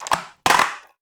Skateboard Intense Flip.wav